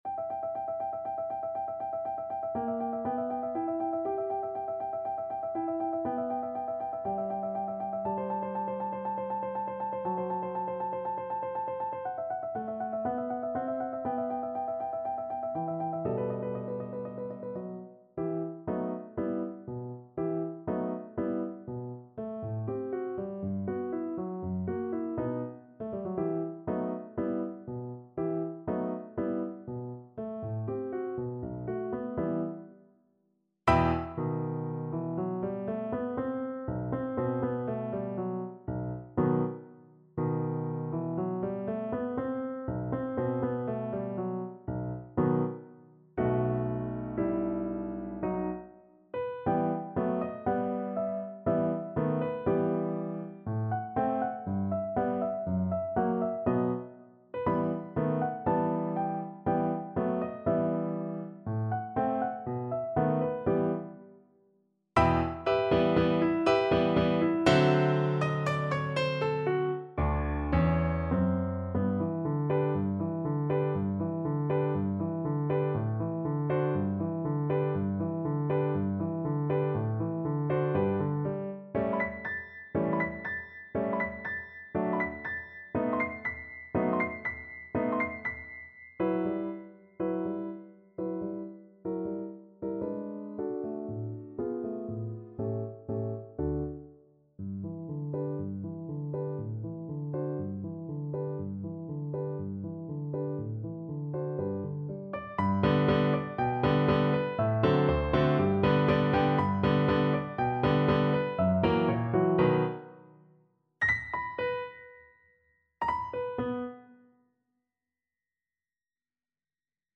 Play (or use space bar on your keyboard) Pause Music Playalong - Piano Accompaniment Playalong Band Accompaniment not yet available transpose reset tempo print settings full screen
E minor (Sounding Pitch) (View more E minor Music for Violin )
= 120 Allegro molto vivace (View more music marked Allegro)
2/4 (View more 2/4 Music)
Classical (View more Classical Violin Music)
kohler_papillon_FL_kar1.mp3